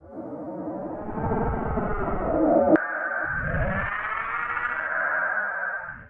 AFX_SPACERADAR_DFMG.WAV